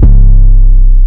808 - Wasted [ C ].wav